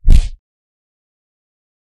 Upper cut punch for a boxing or mma sound effect.